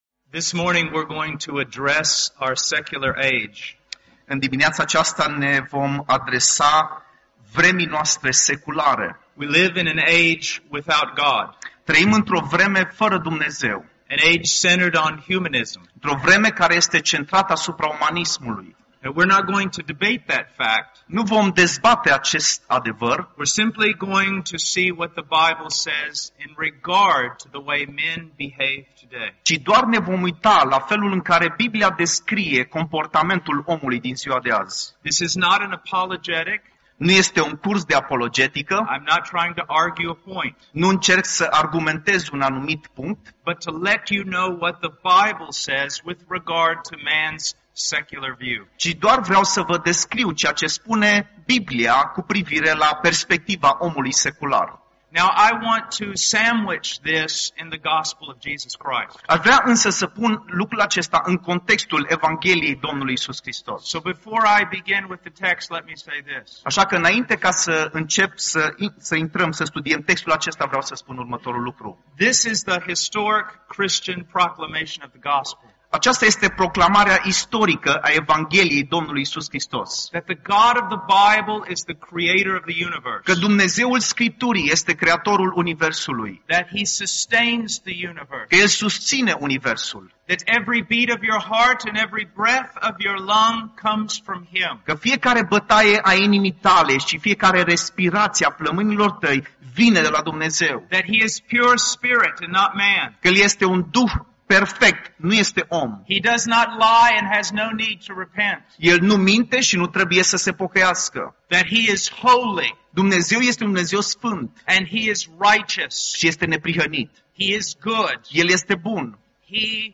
2019 Categorie: Predici Complete Un mesaj despre ce spune Biblia despre lumea seculară în care trăim.